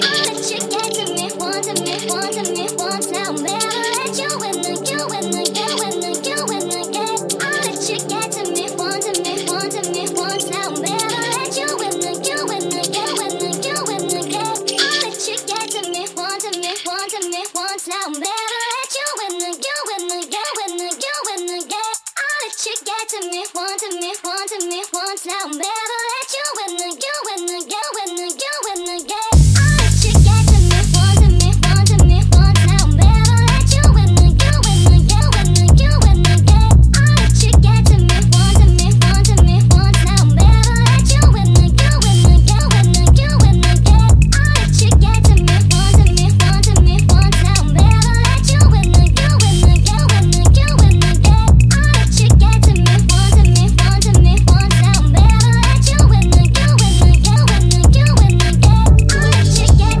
R&Bヴォーカルの早回しのキャッチーな印象と鬼のリーズベースのコンビネーションがナイスなダーク・ガラージ